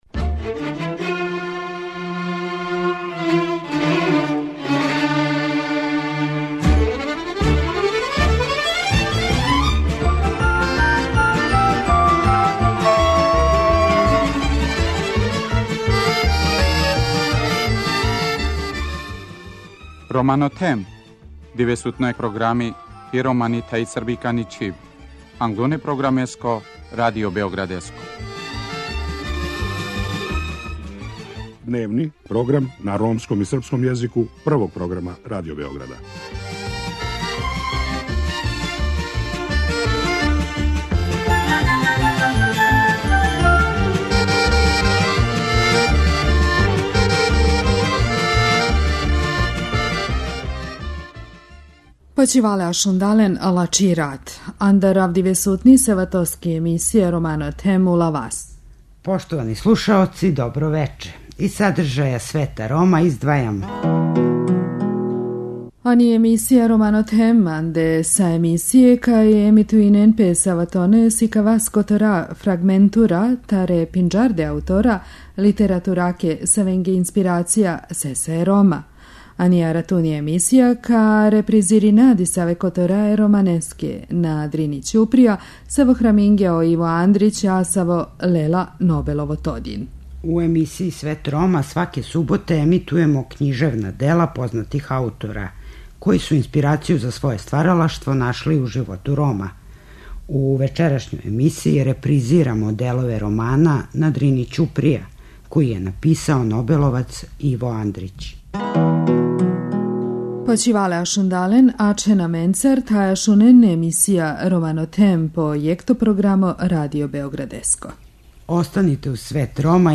У емисији Свет Рома, сваке суботе емитујемо књижевна дела познатих аутора, који су инспирацију за своје стваралаштво нашли у животу Рома. У вечерашњој емисији репризирамо делове романа 'На Дрини ћуприја', који је написао нобеловац Иво Андрић.